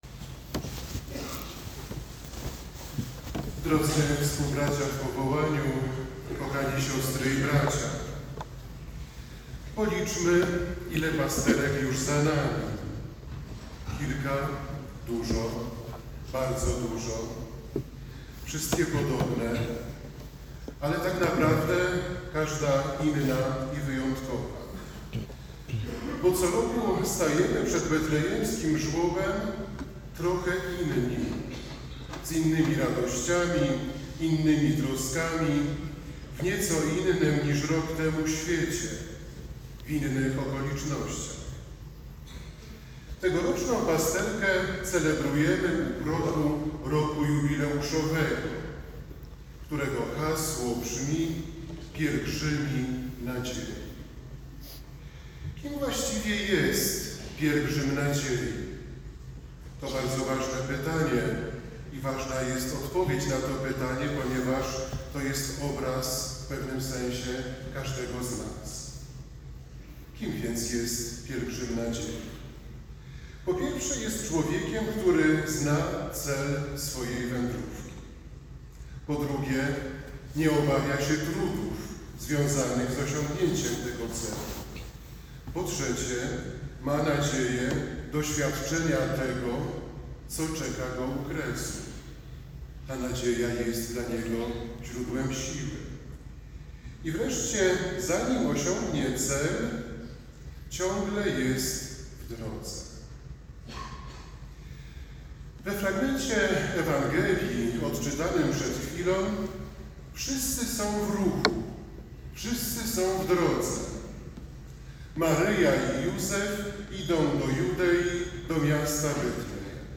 Pasterka w kalwaryjskiej bazylice - Sanktuarium Pasyjno-Maryjne w Kalwarii Zebrzydowskiej
Podczas liturgii wygłosił homilię.